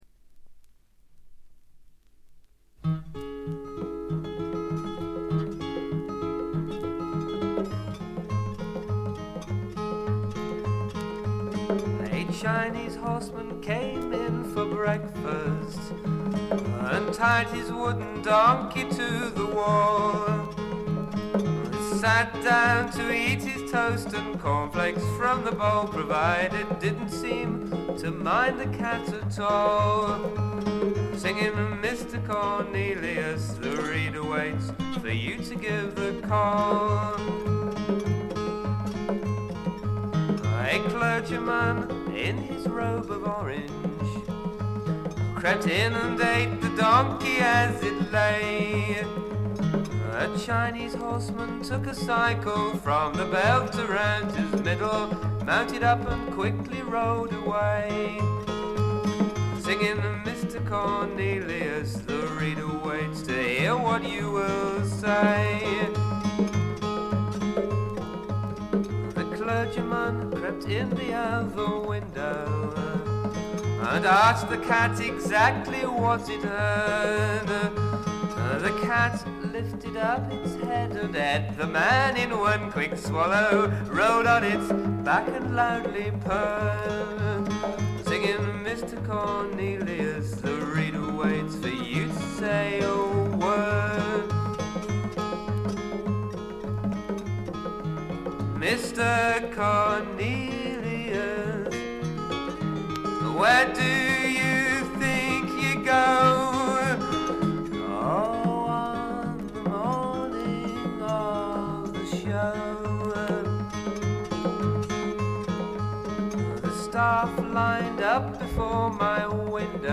わずかなノイズ感のみ。
ラグ、ブルースを下地にしながらも超英国的なフォークを聴かせてくれるずばり名盤であります。
英国のコンテンポラリーフォーク／アシッドフォーク基本盤。
試聴曲は現品からの取り込み音源です。
Vocals, Guitar